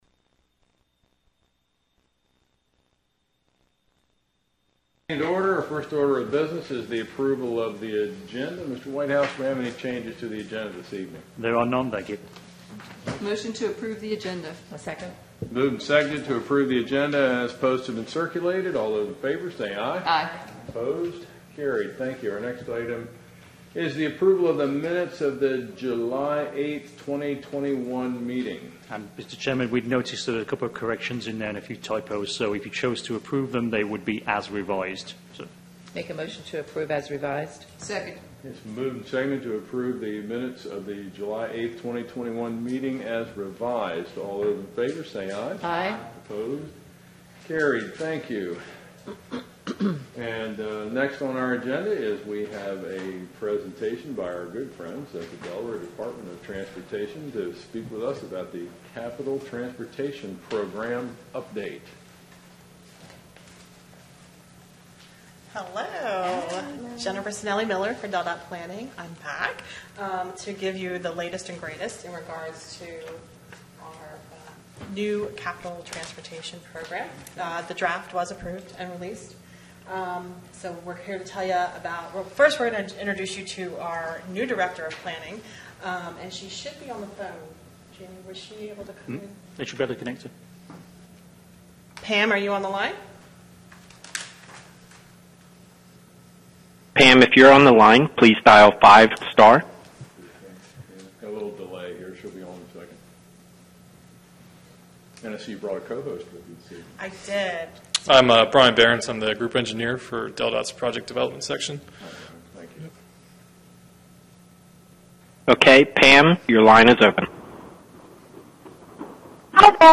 Planning & Zoning Meeting | Sussex County
Meeting location: Council Chambers, Sussex County Administrative Office Building, 2 The Circle, Georgetown